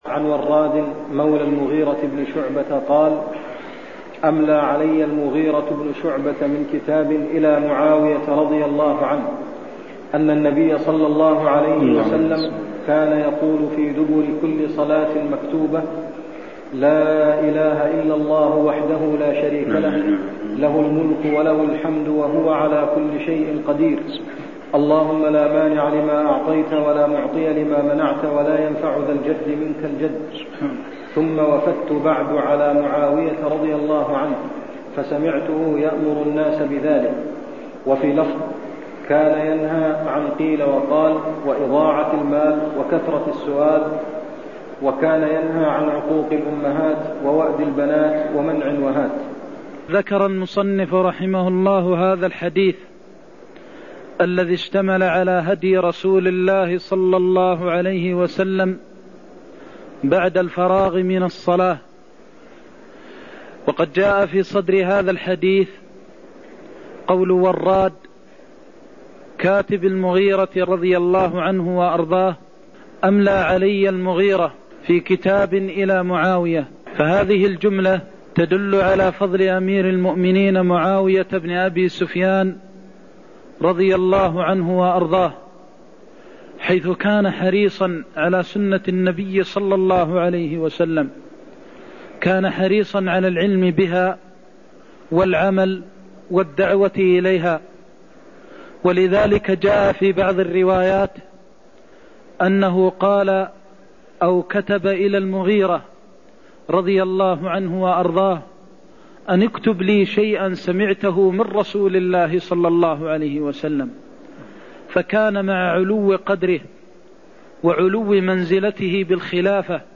المكان: المسجد النبوي الشيخ: فضيلة الشيخ د. محمد بن محمد المختار فضيلة الشيخ د. محمد بن محمد المختار الدعاء بعد الصلاة (123)